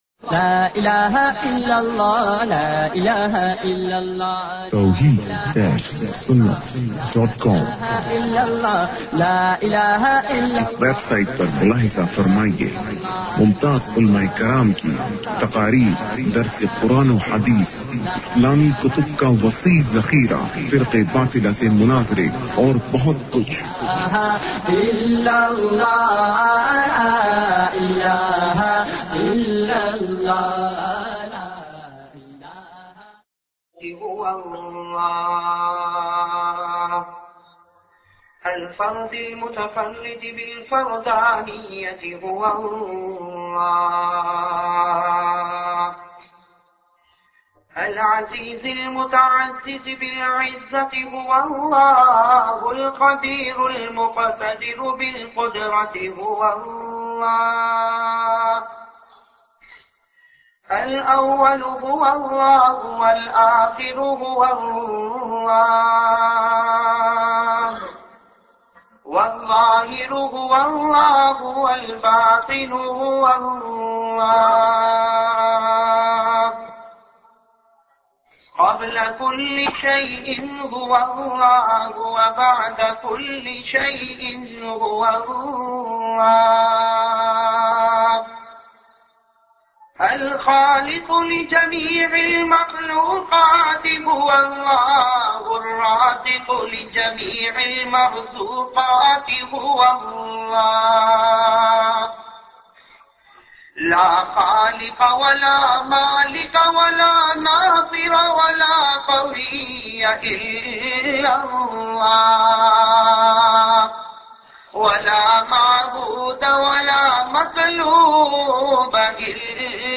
Large collection of Islamic bayan download and listen online on islamicdb.